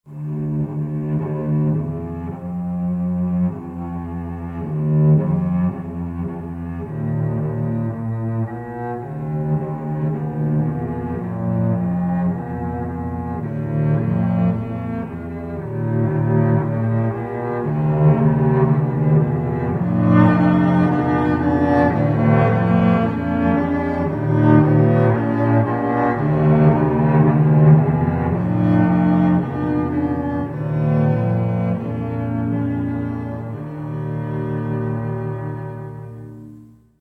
Instrumental
Canons à 2, 3 et 4 voix